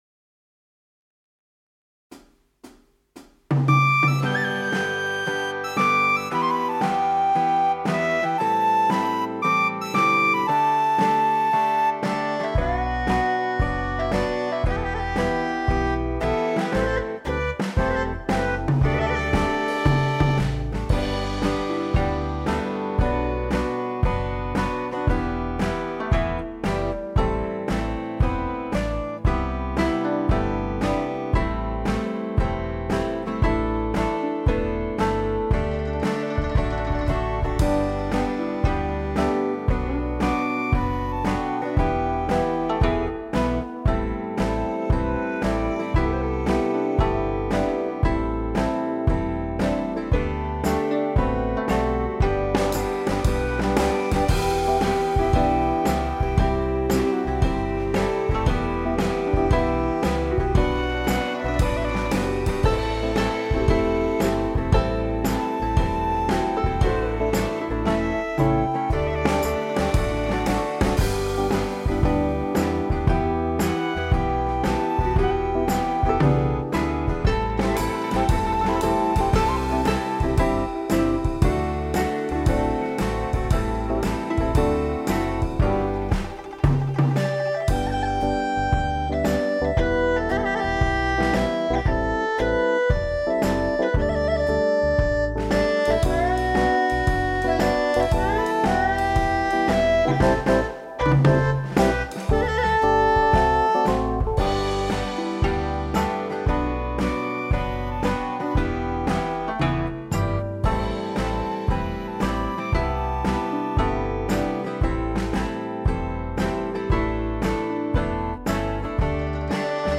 Tone Nữ (G)
•   Beat  01.